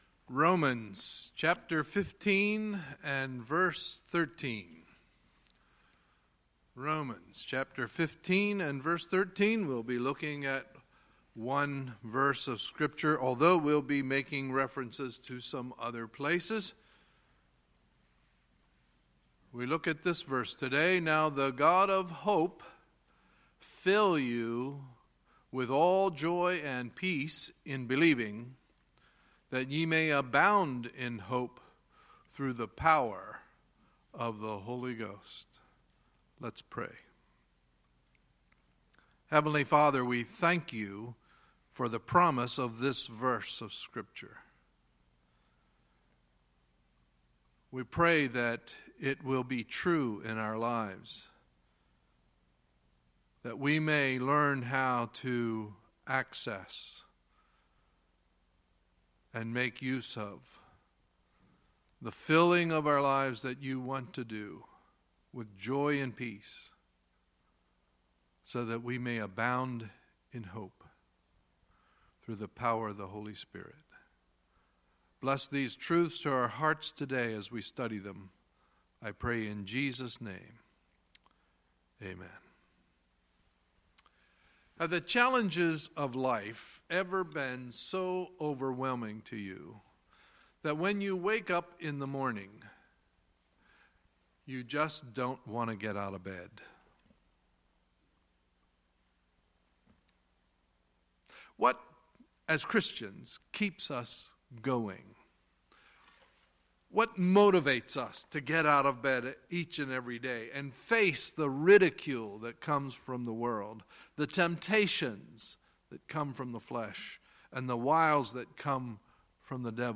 Sunday, July 30, 2017 – Sunday Morning Service